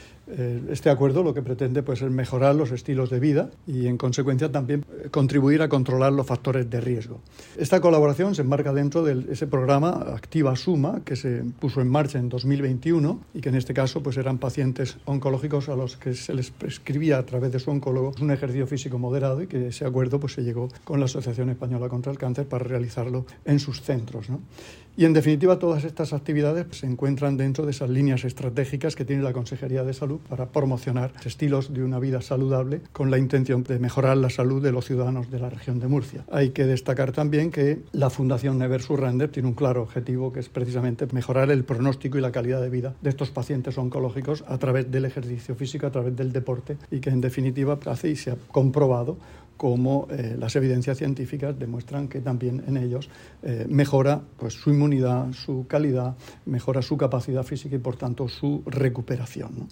Sonido/ Declaraciones del consejero de Salud, Juan José Pedreño, sobre el convenio para potenciar la actividad física entre los enfermos de cáncer agudos.